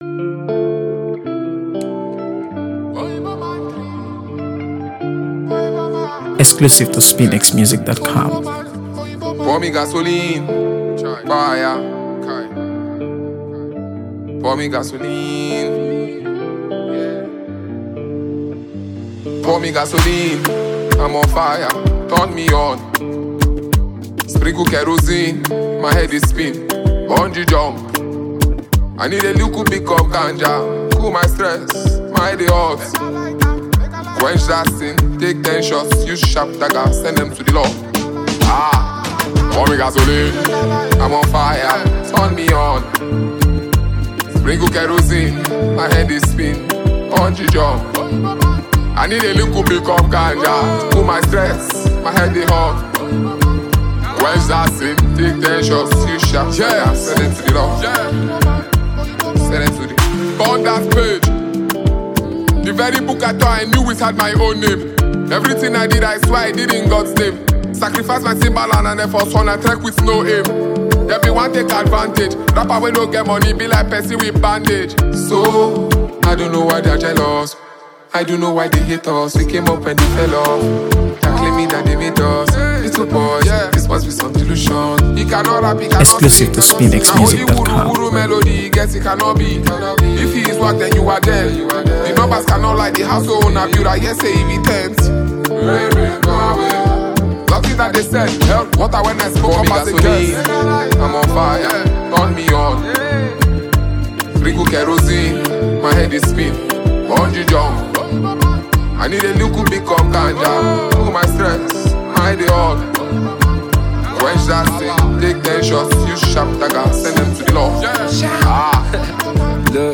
AfroBeats | AfroBeats songs
Nigerian rap
a hard-hitting track that deserves a spot on every playlist.
explosive energy, razor-sharp bars, and a relentless vibe
a gritty, high-octane beat